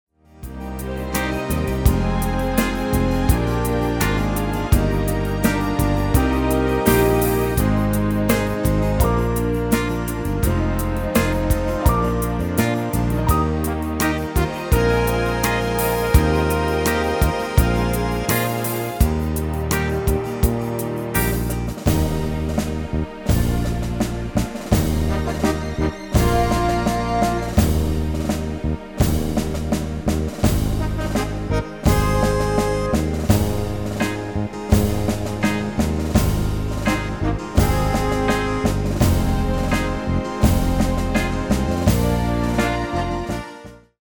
Demo/Koop midifile
Genre: Evergreens & oldies
Toonsoort: Eb
- Vocal harmony tracks